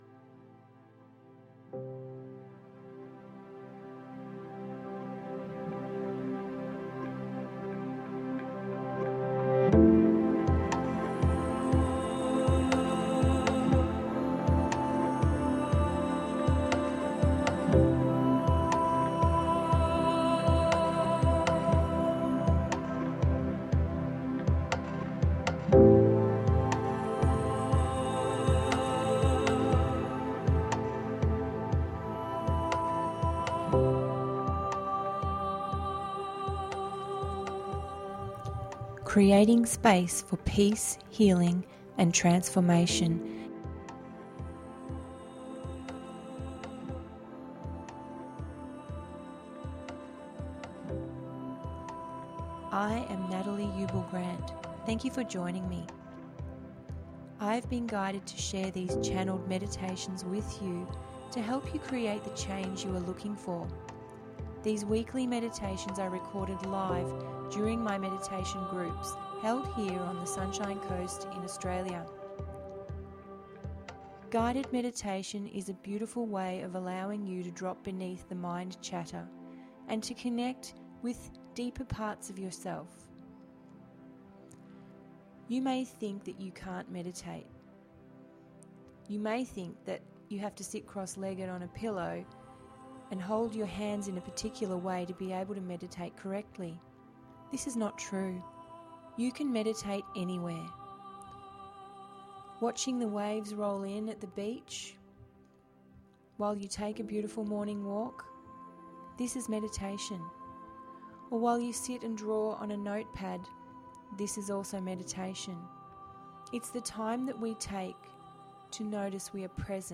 These meditations help you connect with your intuition and awareness and will guide you to insight that flows from within.